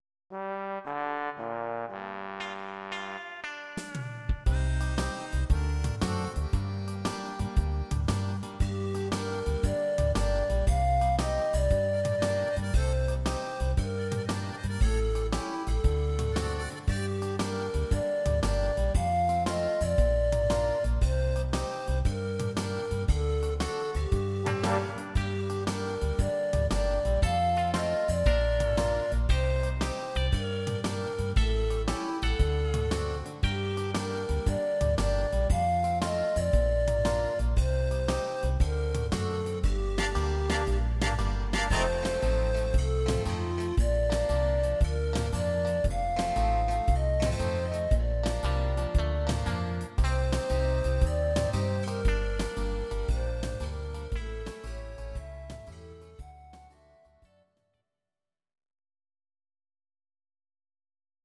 Audio Recordings based on Midi-files
Pop, Oldies, Ital/French/Span, 1960s